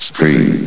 scream.ogg